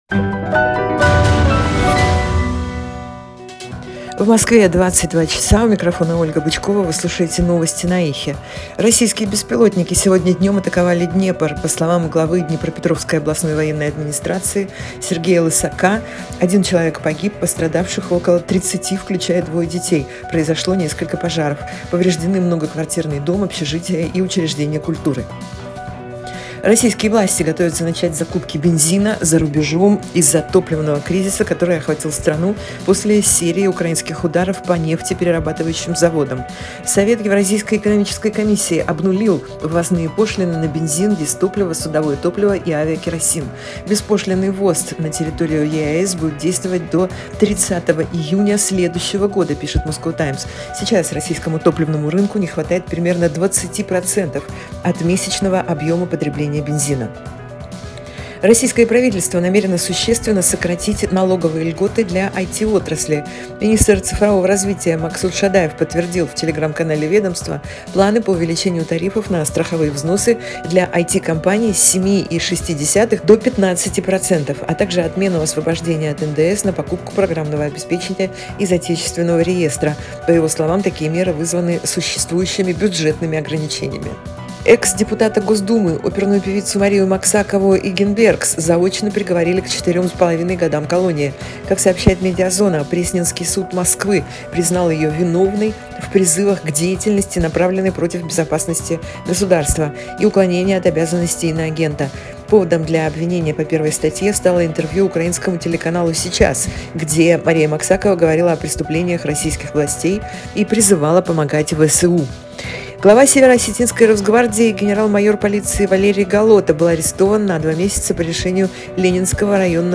Слушайте свежий выпуск новостей «Эха»
Новости 22:00